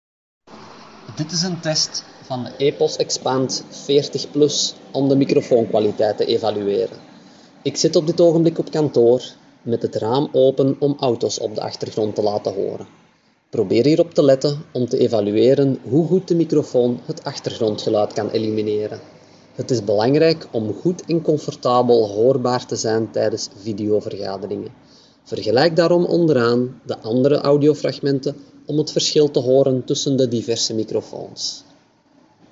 As tastes differ, we have provided below a series of sound clips in which we test the microphone of a webcam, laptop, speakerphone, headset and earphone, among others.
Epos Expand 40+ (Handsfree):